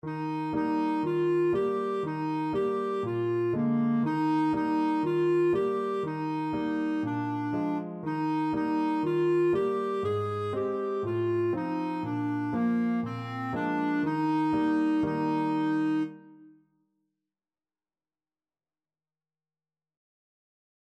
Clarinet
4/4 (View more 4/4 Music)
Bb4-Ab5
Eb major (Sounding Pitch) F major (Clarinet in Bb) (View more Eb major Music for Clarinet )
Traditional (View more Traditional Clarinet Music)